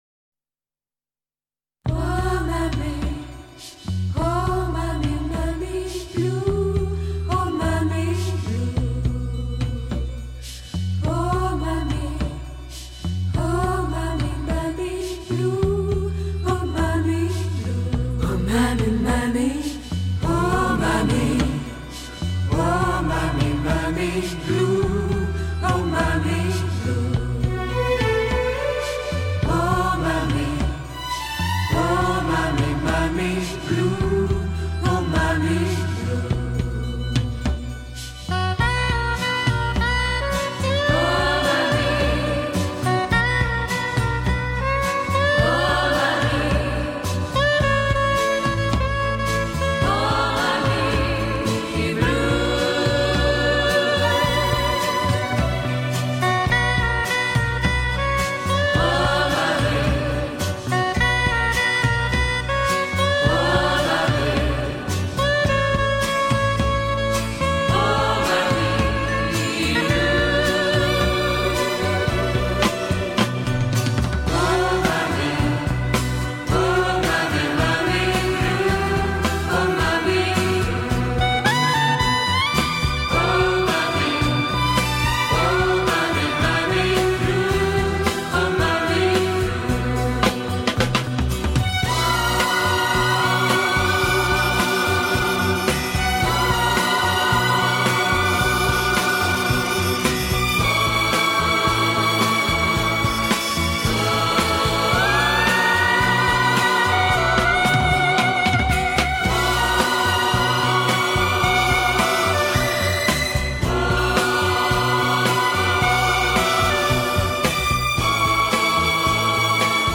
人类音乐史上最优美的轻音乐全集 大师示范级经典作品